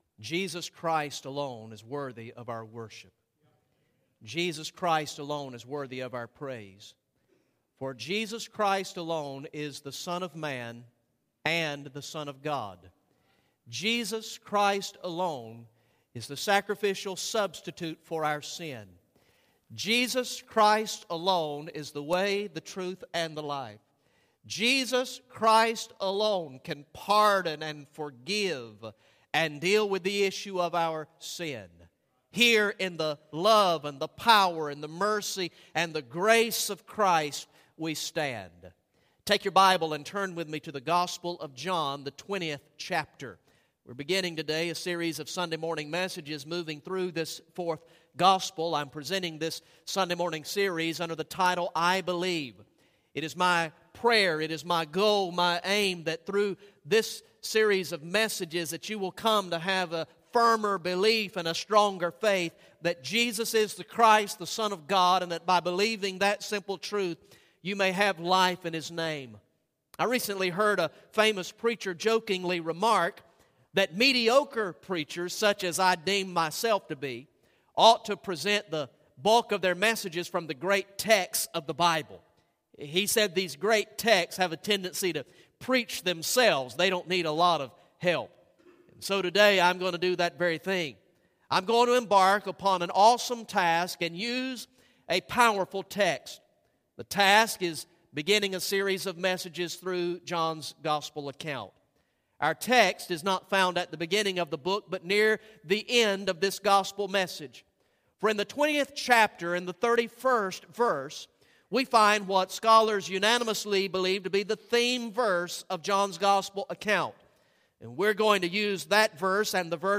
Message #1 from the sermon series through the gospel of John entitled "I Believe" Recorded in the morning worship service on Sunday, February 9, 2014